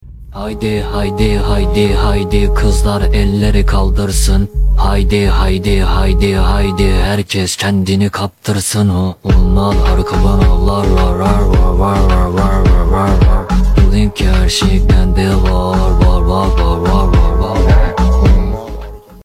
İtoshi Rin is singing hav hav hav with his own voice
You Just Search Sound Effects And Download. tiktok funny sound hahaha Download Sound Effect Home